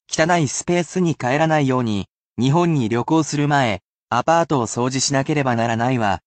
However, it will be at normal speed which may be difficult for beginners, but should assist you in getting used to the speed of the language, but this will act as useful listening practise.
[casual speech]